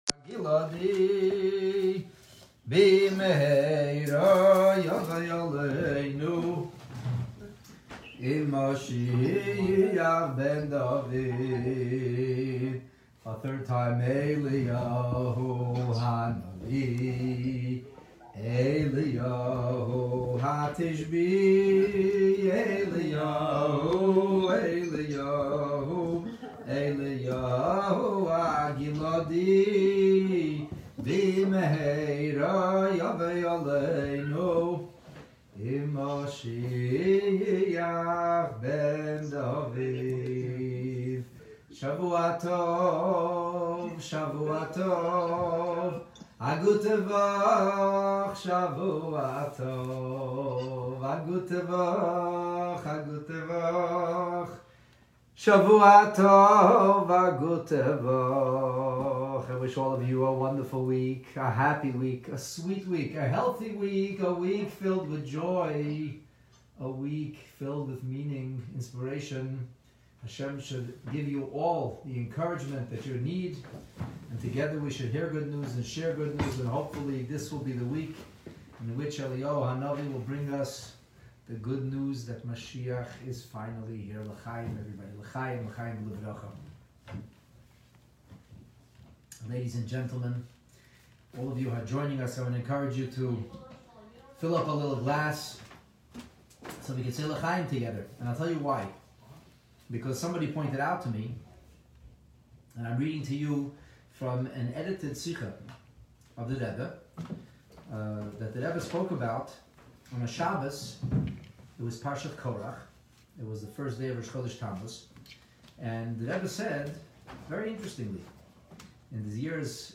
Motzei Shabbos Farbrangen - Rosh Chodesh/Bais Iyar